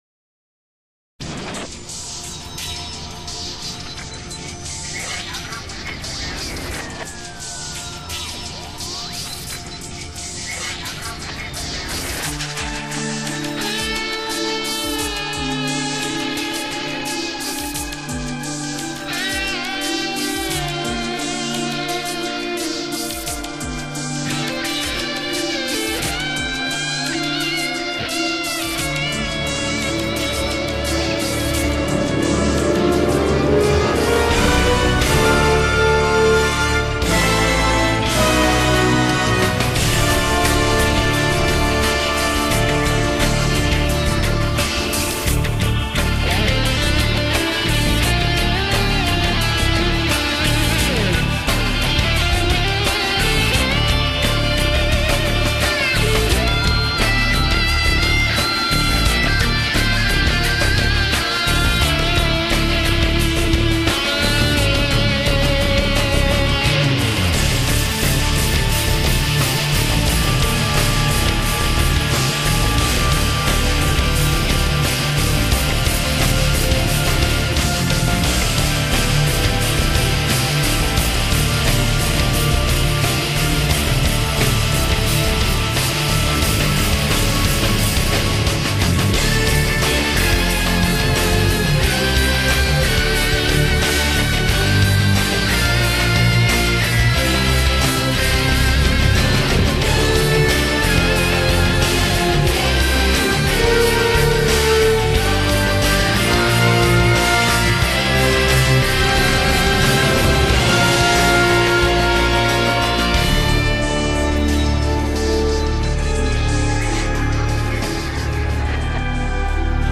Por ello, este tema instrumental, les viene al dedillo.